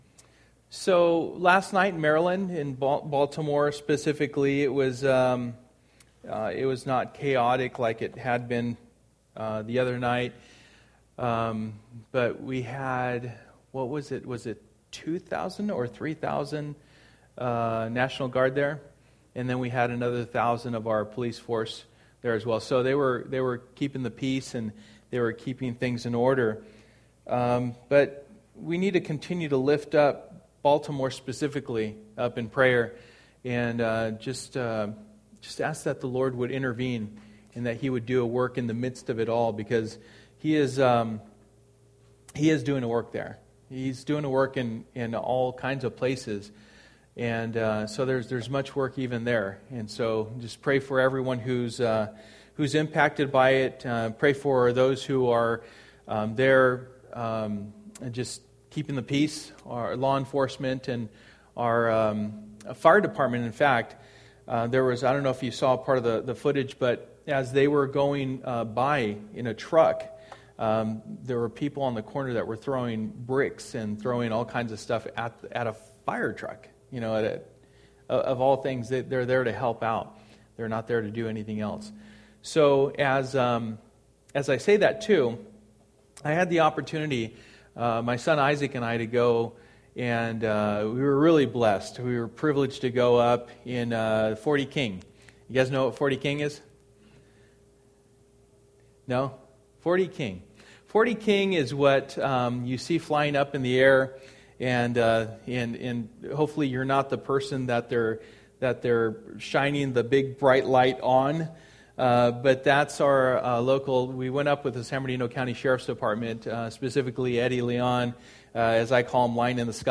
Through the Bible Service: Wednesday Night %todo_render% « Pay Attention and Do It!